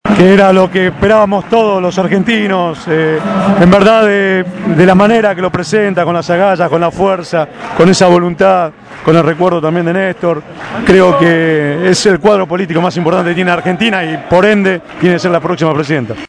registró para los micrófonos de Radio Gráfica FM 89.3 los testimonios de la jornada.